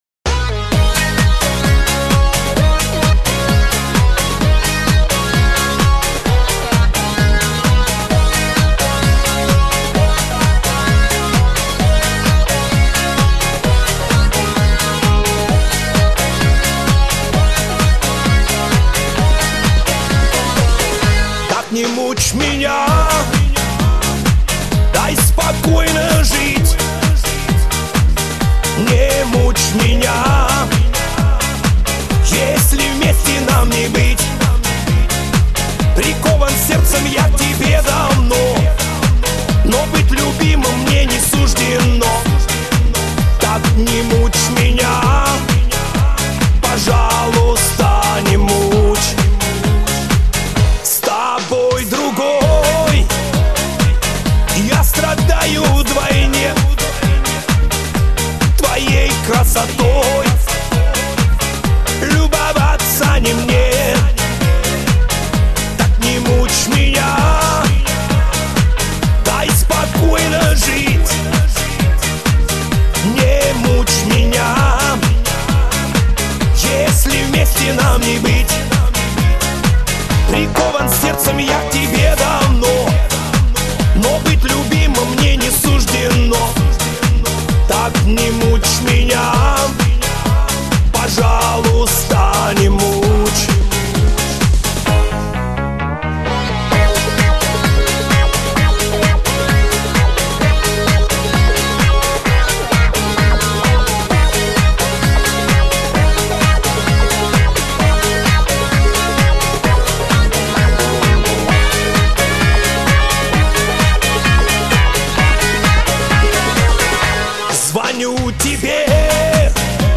Назад в *Шансон*
Слушаем настоящий Шансон)))